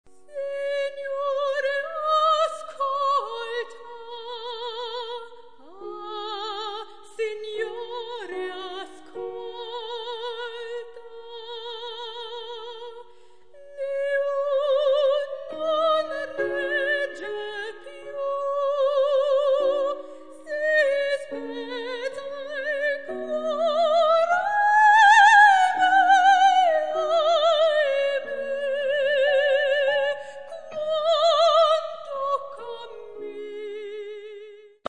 Sopran
Flügel